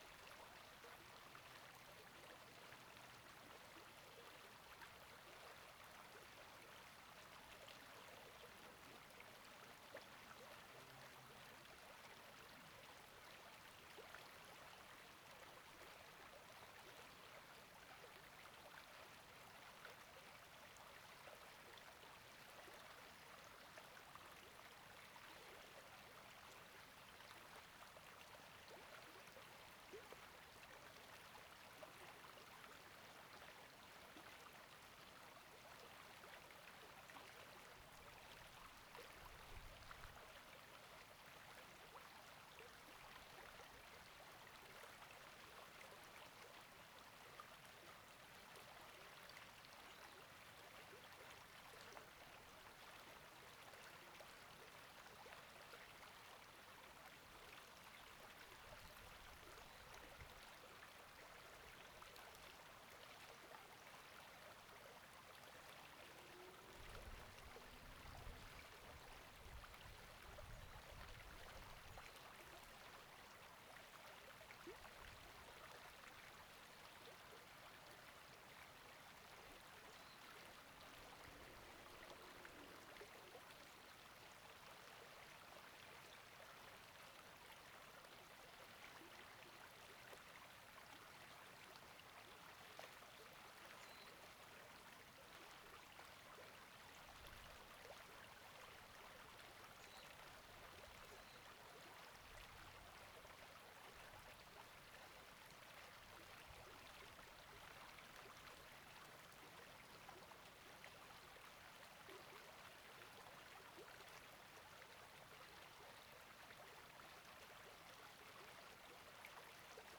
「黒目川の流れ」　2020年5月24日
指向主軸角度　120度に設定
レコーダー／PCM-D10